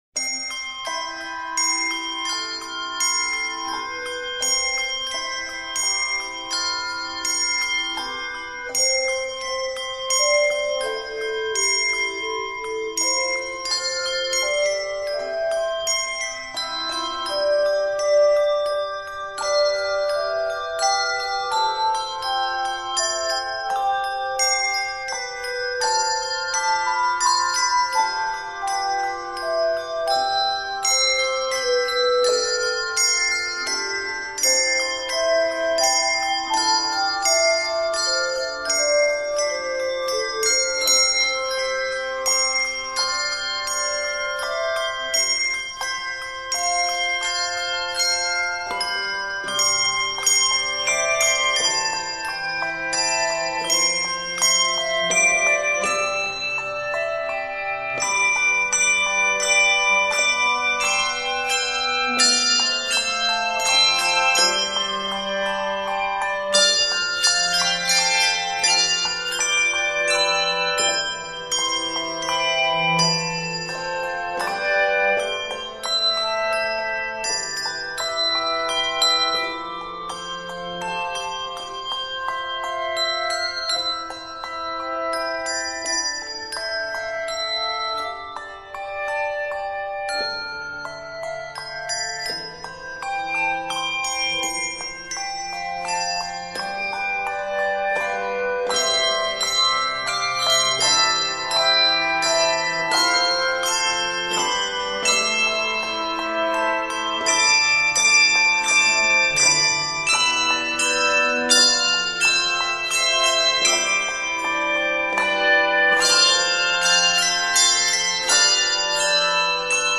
This flowing arrangement
Arranged in C Major, this piece is 66 measures.